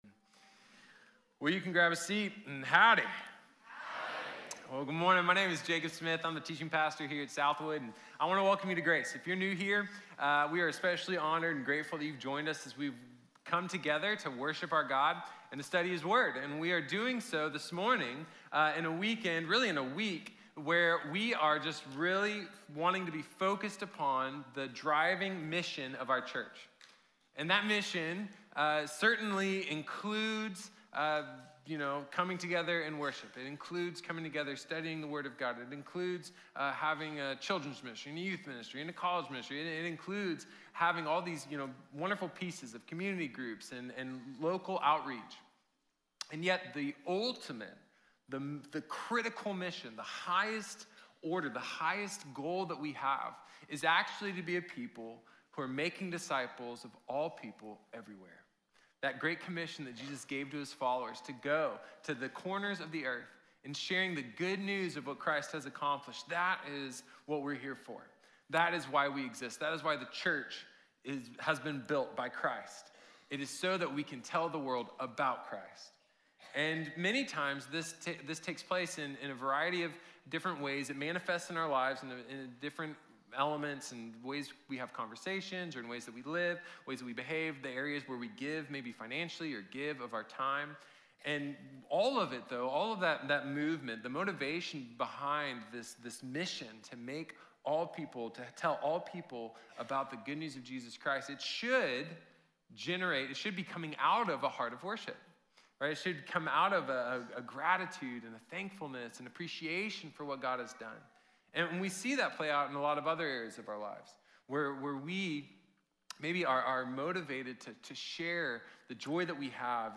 Worship & Witness | Sermon | Grace Bible Church